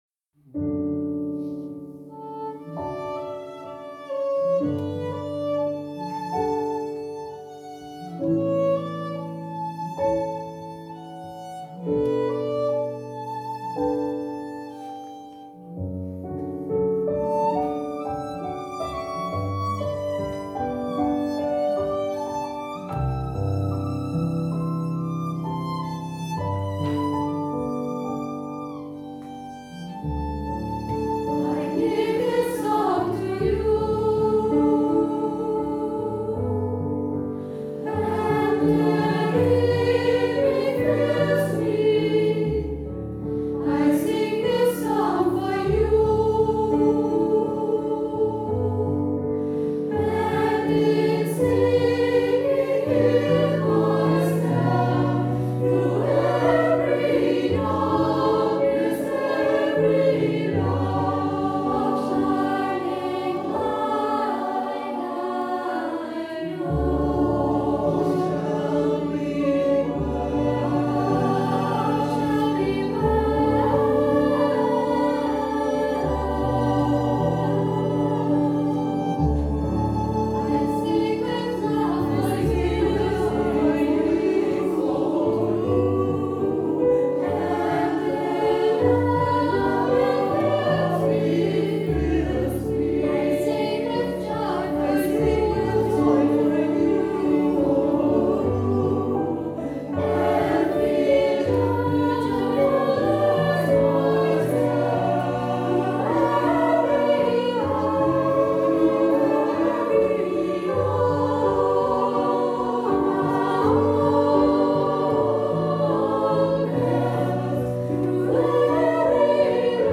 KOJE-Frauenchor
KOJE vocal
am 8. Juni 2024  in Maienfels
Violine